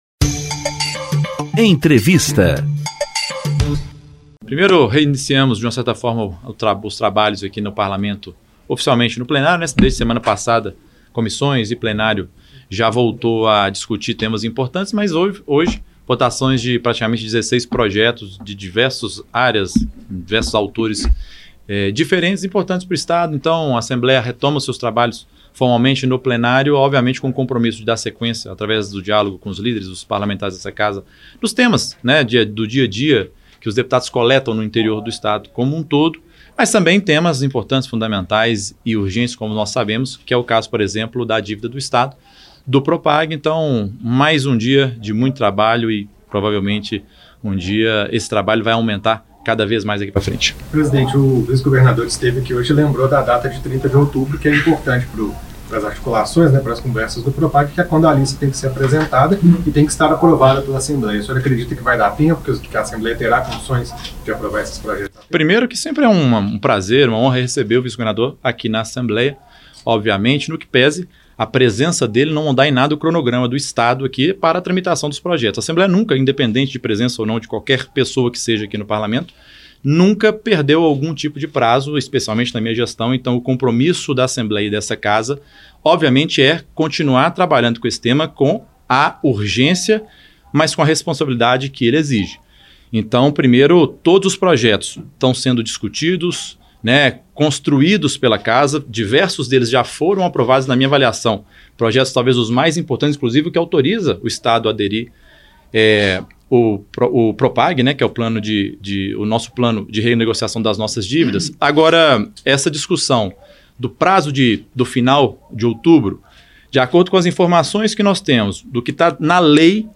Na íntegra da entrevista coletiva, o Presidente da Assembleia Legislativa fala da retomada das votações no Plenário e comenta fatos recentes sobre o trâmite dos projetos do Programa de Pleno Pagamento de Dívidas (Propag).